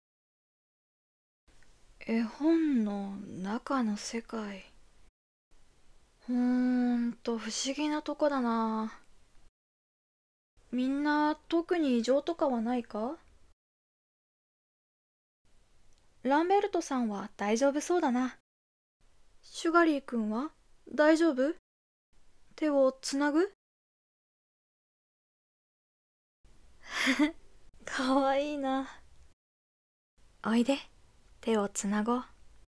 🪔声劇①